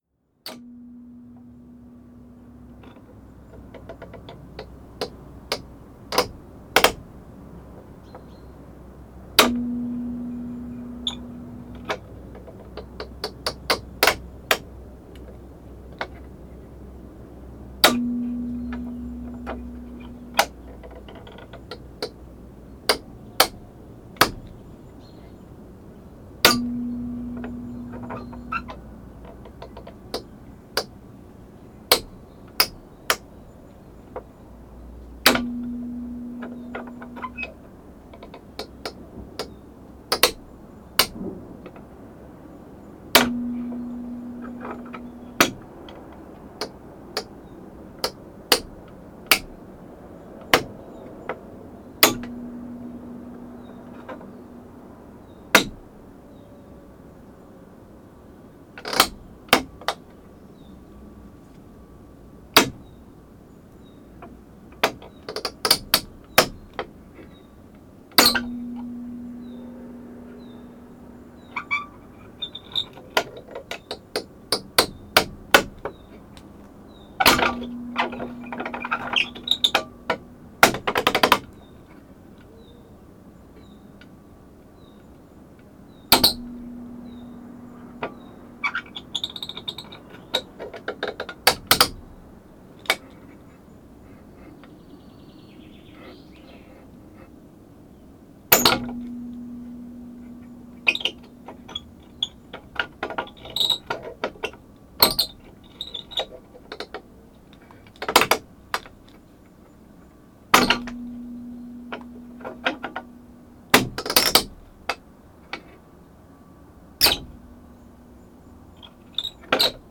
Chave em porta de casa em ruínas no pinhal à entrada poente de Boa Aldeia, Boa Aldeia a 22 Março 2016.
NODAR.00529 – Boa Aldeia, Farminhão e Torredeita: Chave em porta de casa em ruínas no pinhal à entrada poente de Boa Aldeia